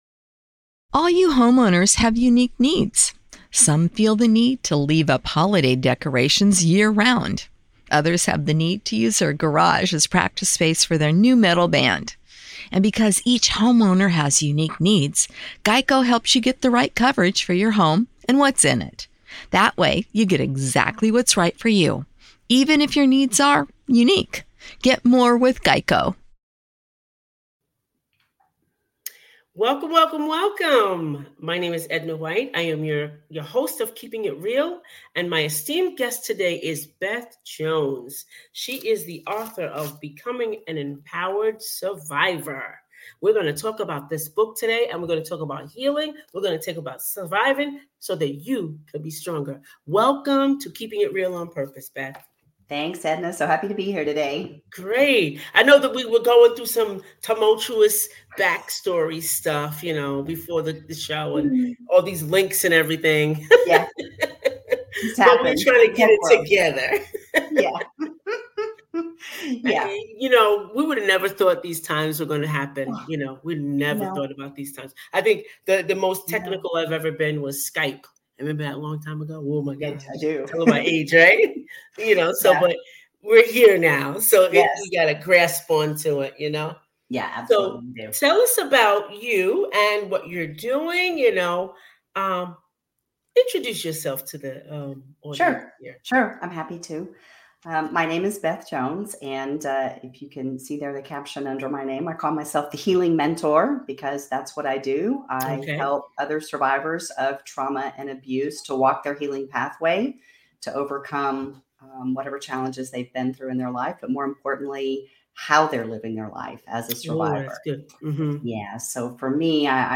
Join us for a candid and inspiring conversation as we explore the path to recovery and the hope that lies beyond trauma.